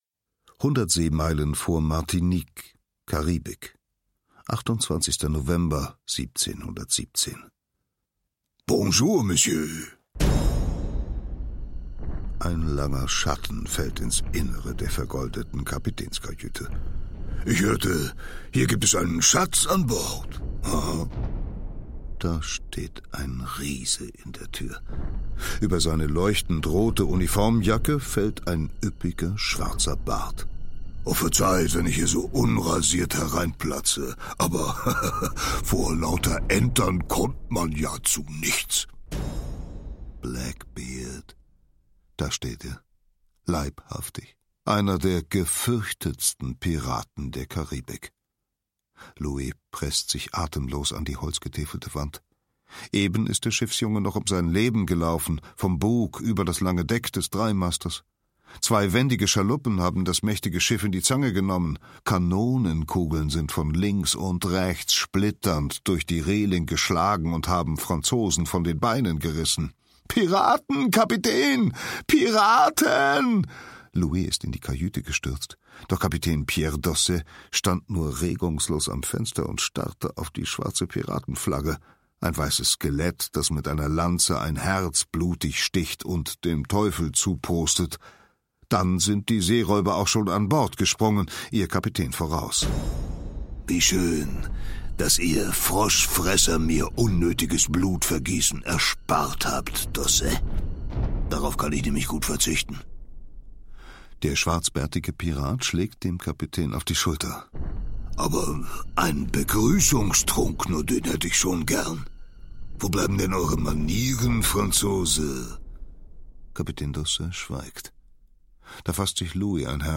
Schlagworte GeschichteHistorie • Hörbuch; Lesung für Kinder/Jugendliche • Kinder, Jugendliche und Bildung • Piraten • Pirat / Piraterie / Seeräuber; Kinder-/Jugendlit.